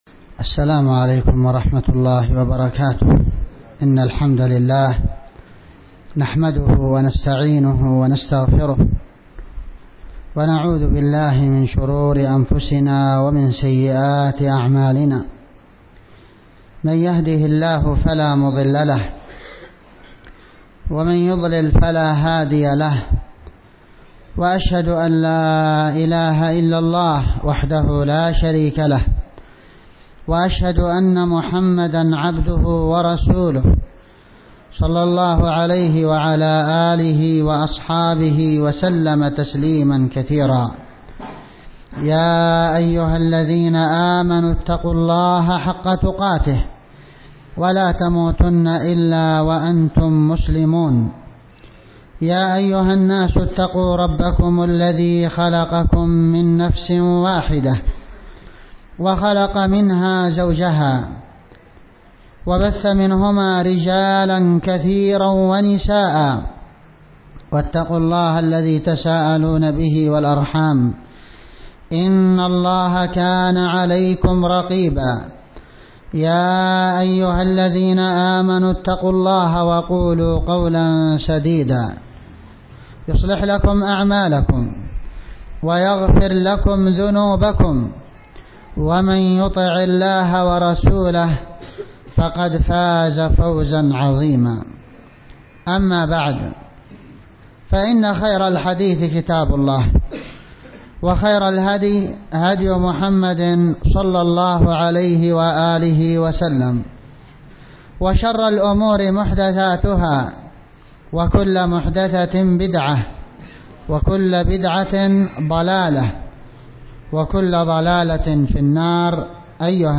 محاضرة بعنوان: من عادى لي وليا فقد أذنته بالحرب - في مسجدالبيرين7شعبان1440هـ
DZ8Gn.من عادى لي وليا-محاضرة في مسجدالبيرين.mp3